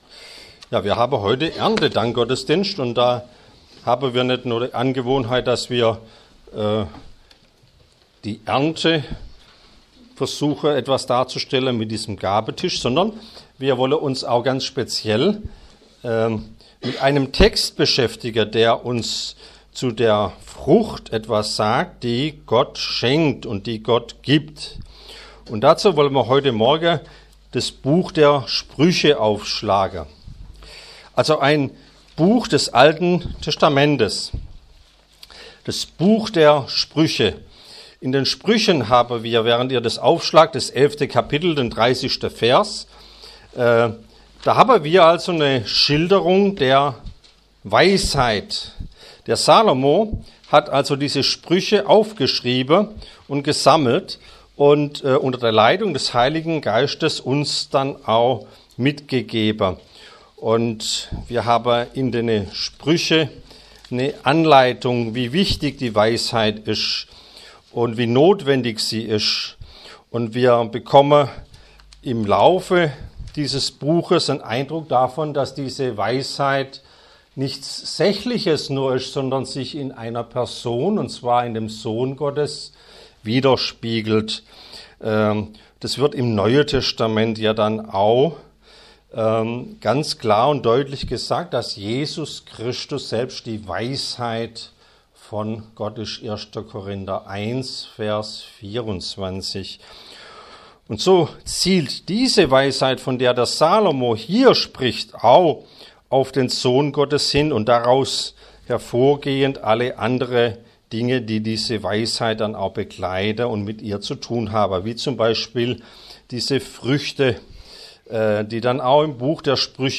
Erntedankgottesdienst – Die Frucht des Gerechten – Freie Reformierte Gemeinde Neuenstein